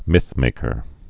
(mĭthmākər)